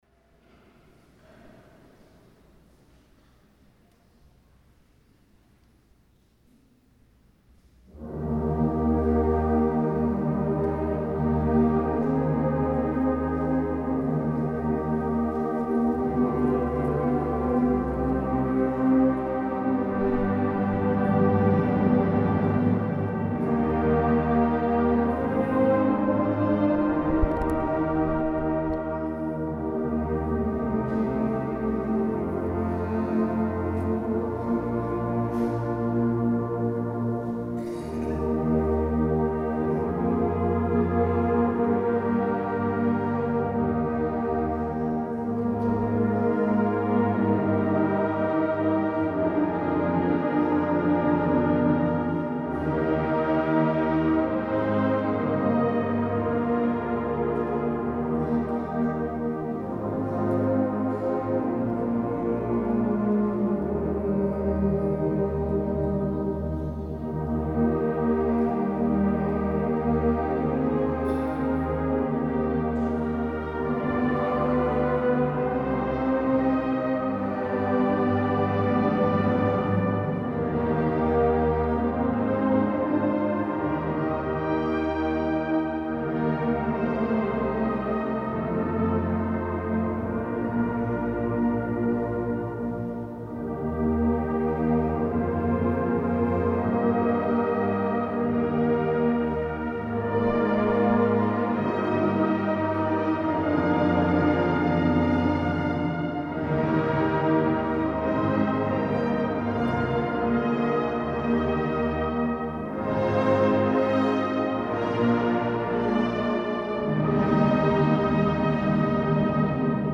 Oer Hymne | Koraalorkest Hymne
It orkest wurdt wolris in brassband plus neamd, omdat der oan de brassband trije saksofoans en inkele trompetten taheakke binne.